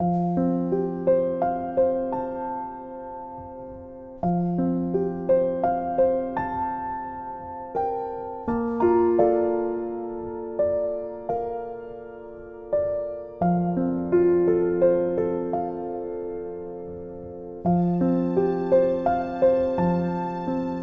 piano.mp3